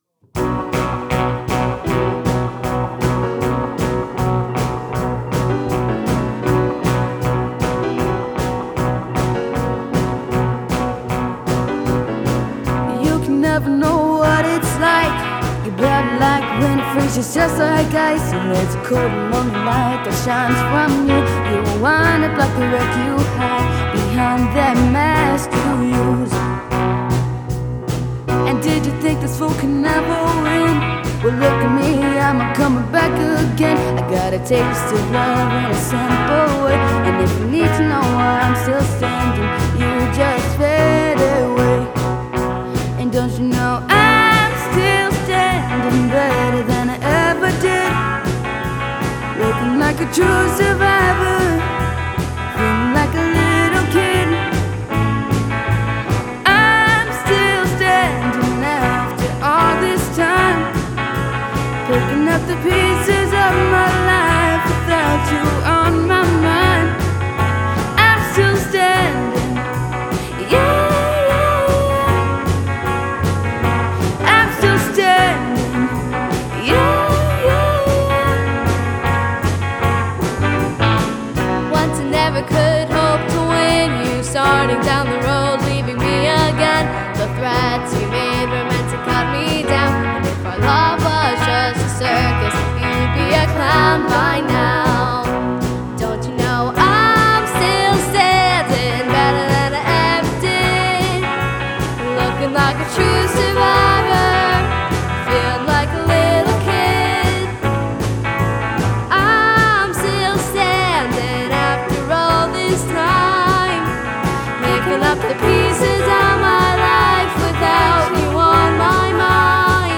The class will learn how to work collaboratively while performing covers of pop and rock tunes, meeting hourly once a week.
New and current students are welcome: the class is open to all instruments including drums, keyboard, bass, sax, guitar, trumpet, trombone, and voice.
Check out a recent recording from this class, performing “I’m Still Standing” by Elton John!